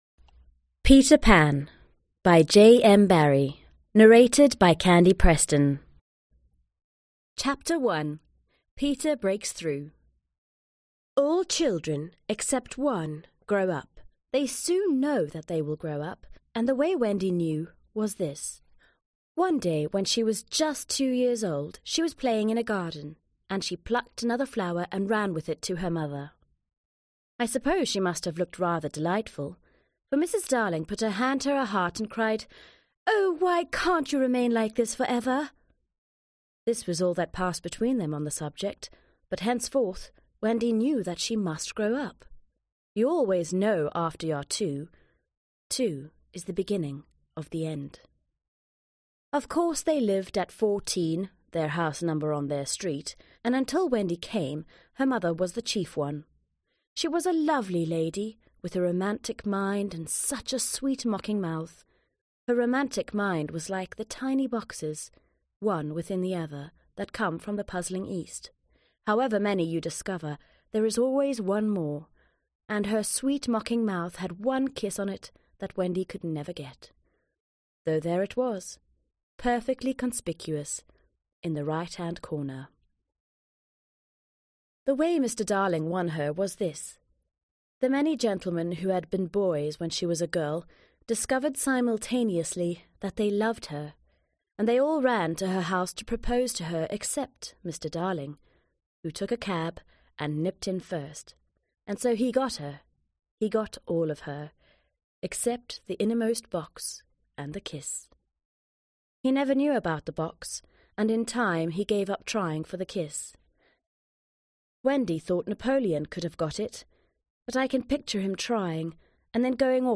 Аудиокнига Peter Pan | Библиотека аудиокниг